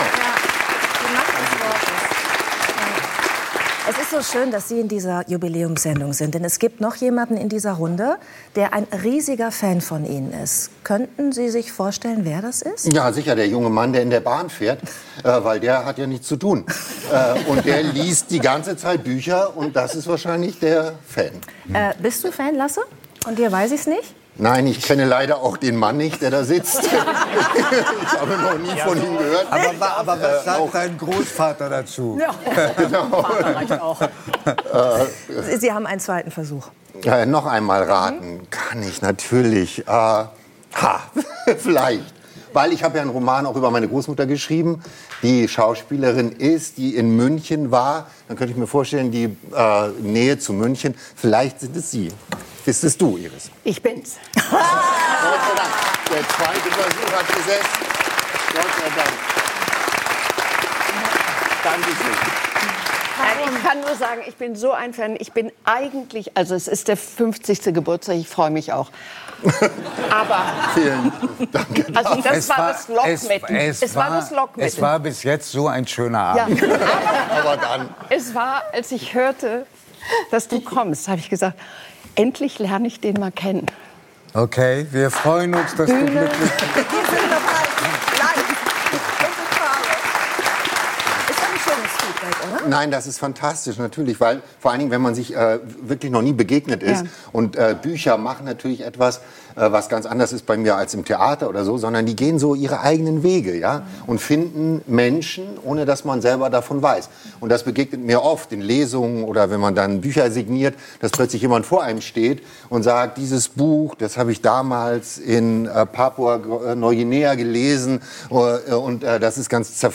Beschreibung vor 1 Jahr Er ist ein brillanter Erzähler mit norddeutschem Einschlag und 3nach9 freut sich sehr, ihn in der Schar der Gratulanten begrüßen zu dürfen: den preisdekorierten Schauspieler Joachim Meyerhoff.